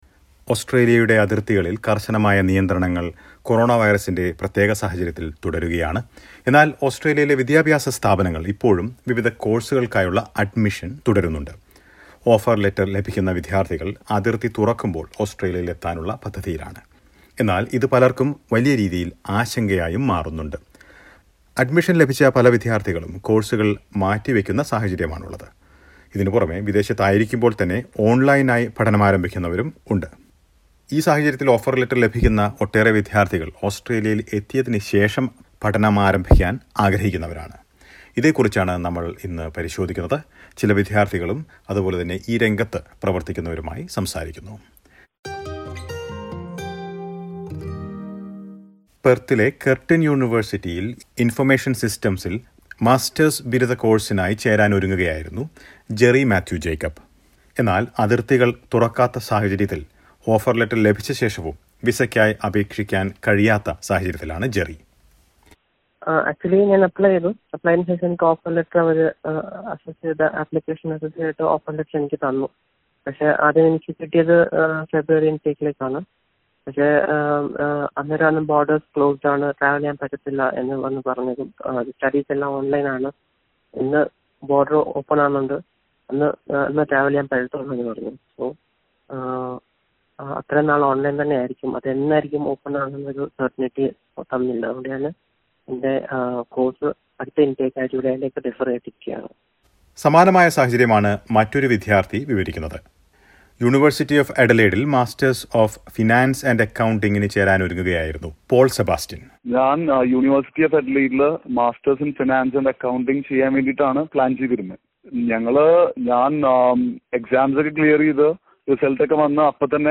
Due to border restrictions, new international students are not able to come to Australia. Many have postponed their courses. Listen to a report.